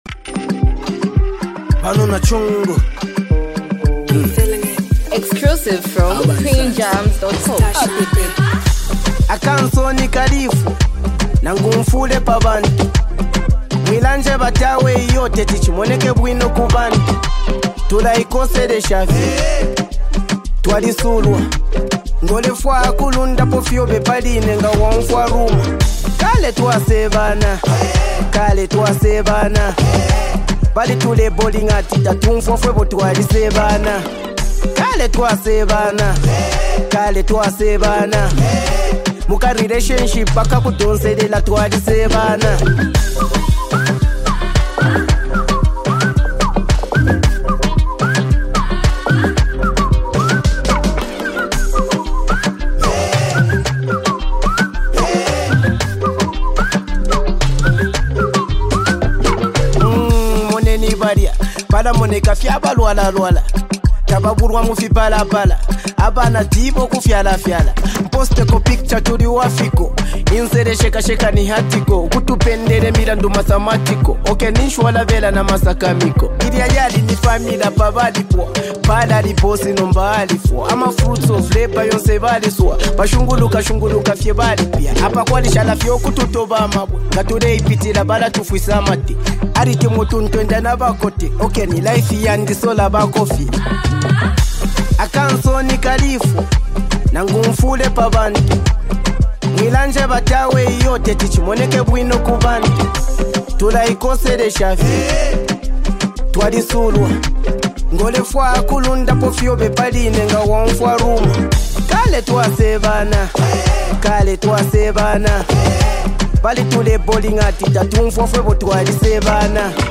deep storytelling track
melodic hook brings a sense of sorrow and acceptance